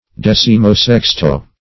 decimosexto - definition of decimosexto - synonyms, pronunciation, spelling from Free Dictionary
Decimosexto \Dec`i*mo*sex"to\, n. [Prop., in sixteenth; fr. L.